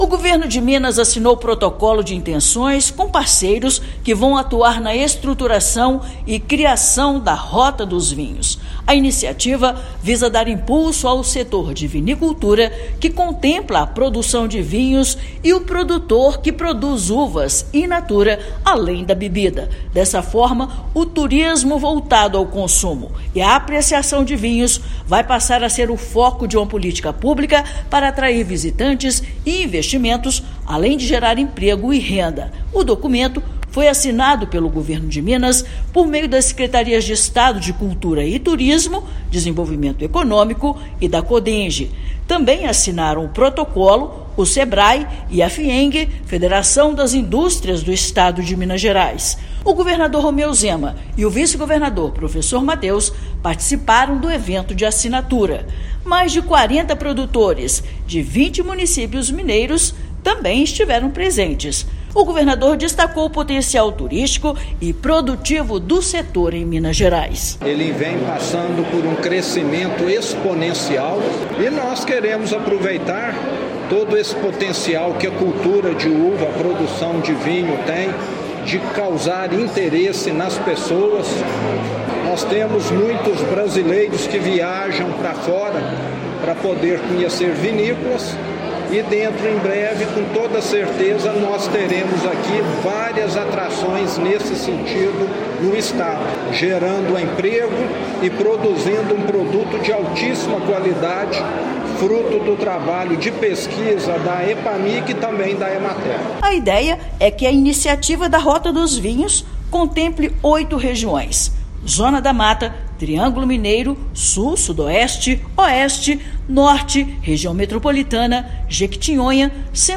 Setor, que está em crescimento em todo o país, será potencializado em Minas para atrair mais visitantes. Ouça matéria de rádio.